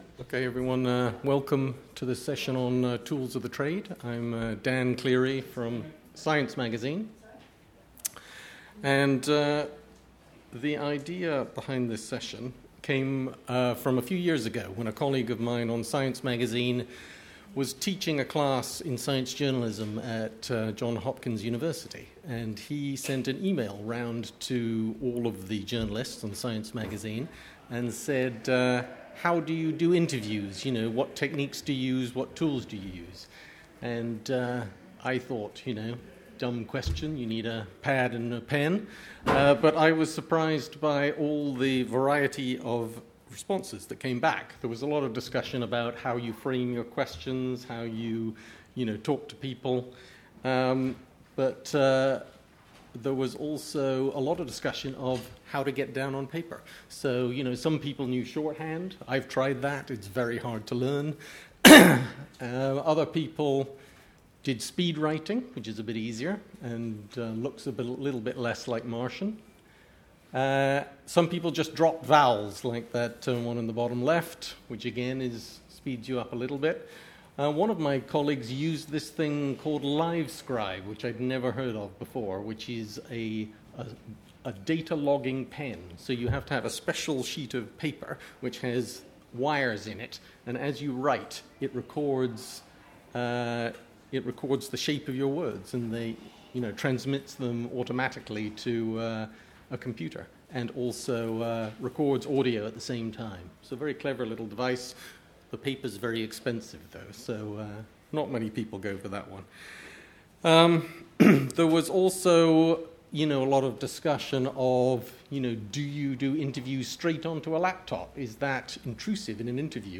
Audio: UK Conference of Science Journalists panel on tools for journalists